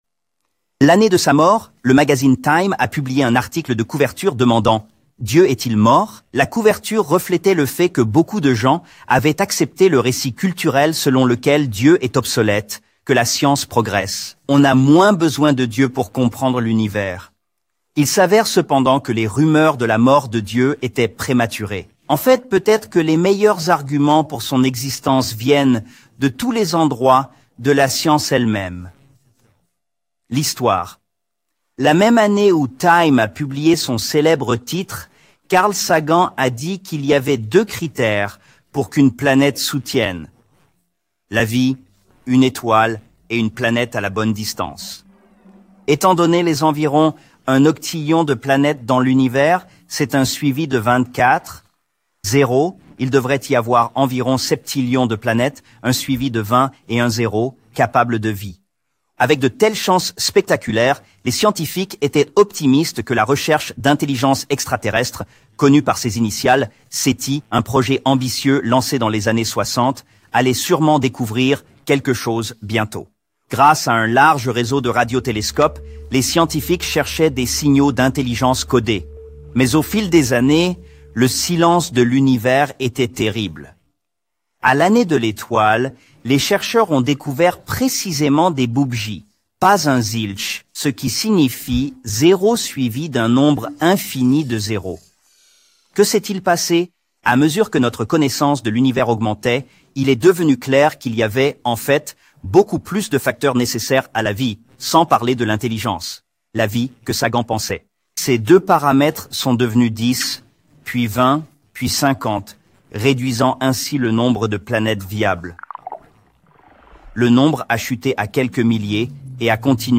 Description: Dans cette vidéo, Eric Metaxas parle de la manière dont de nombreux grands athées du monde entier soutiennent l'existence de Dieu.